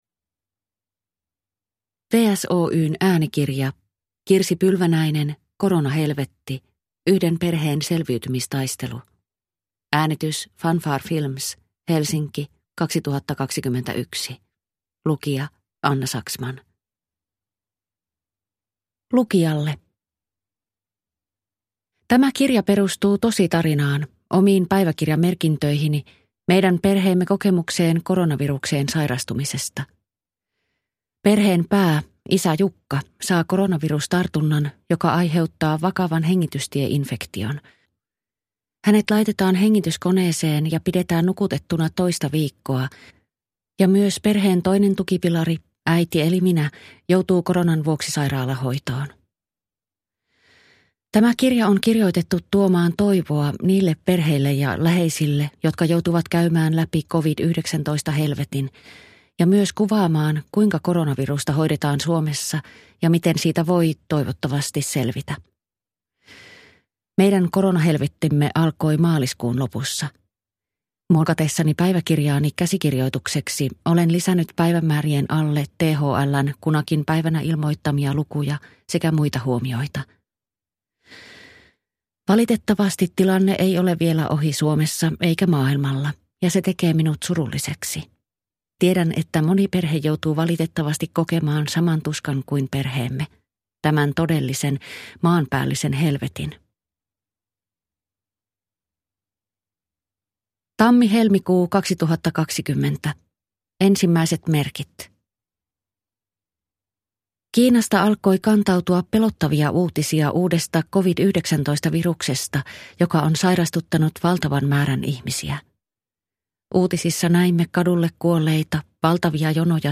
Koronahelvetti – Ljudbok – Laddas ner